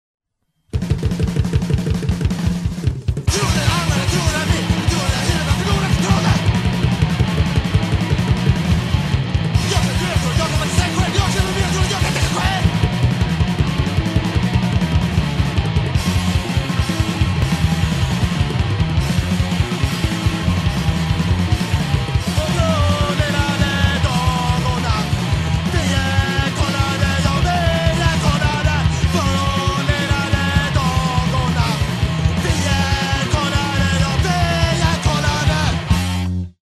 a quartet, who tried to play fast punk
Guitar, voice
Drums